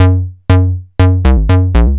TSNRG2 Bassline 010.wav